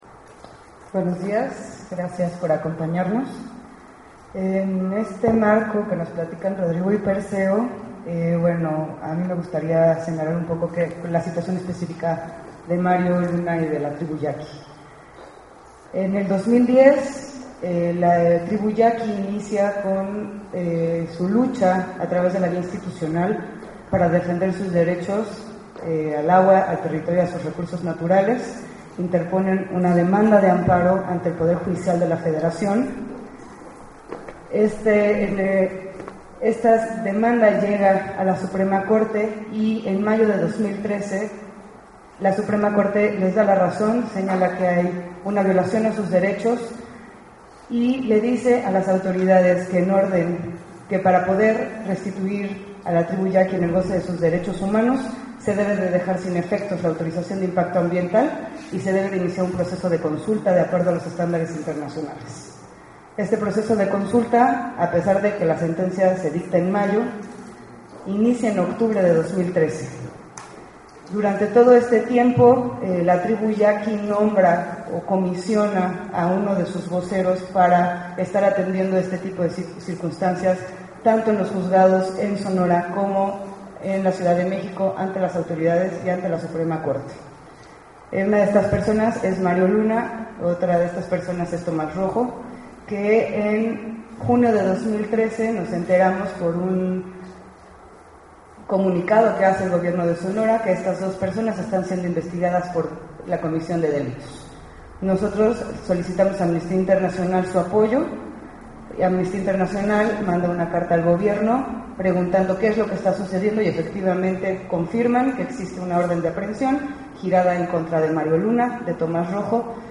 En conclusión los conferencistas concuerdan con  tres puntos:  en México las obras se realizan sin estudios previos de impacto ambiental, se desacata las sentencias de la SCJN y la nueva ley de amparo no funciona debidamente.